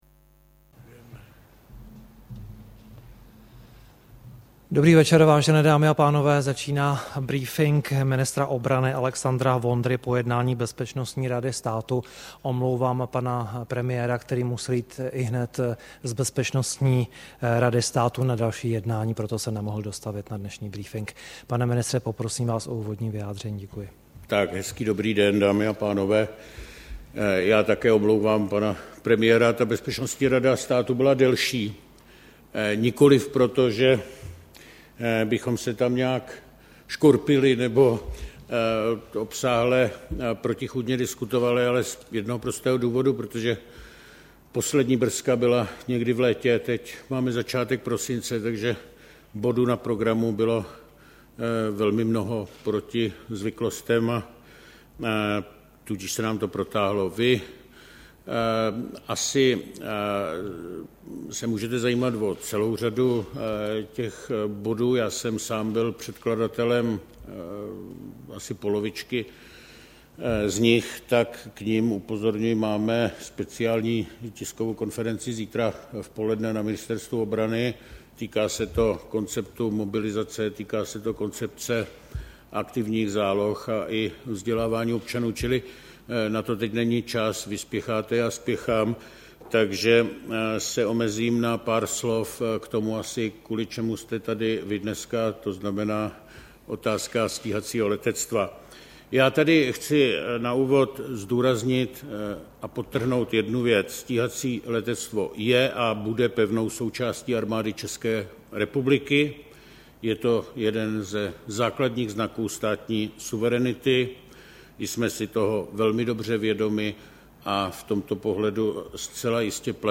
Tiskový brífink po jednání Bezpečnostní rady státu, 3. prosince 2012